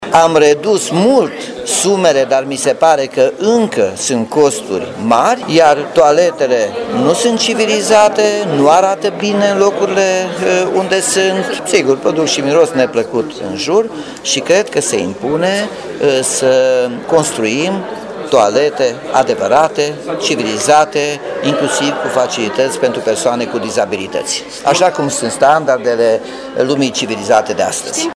Cu ocazia dezbaterilor din plen, primarul Nicolae Robu a spus că toaletele ecologice din oraș trebuie înlocuite cu unele mult mai civilizate: